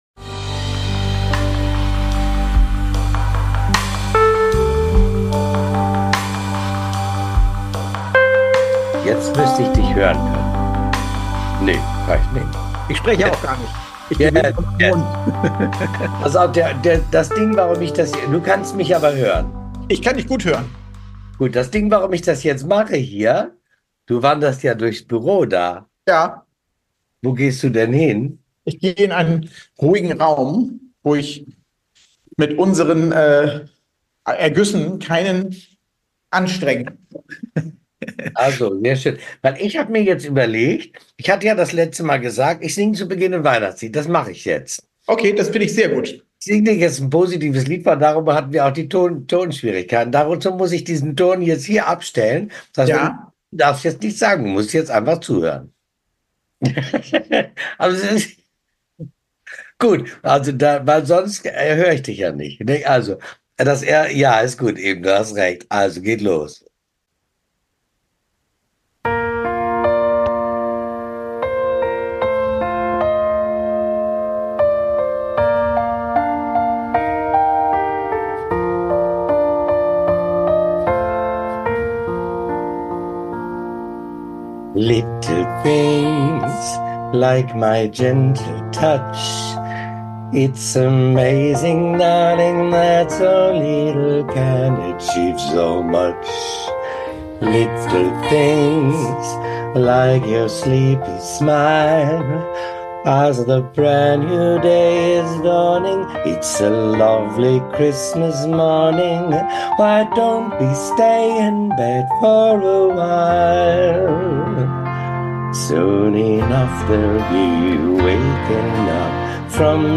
Ein entspanntes Gespräch. Aufgezeichnet im wunderbaren Hotel Zumnorde in Erfurt.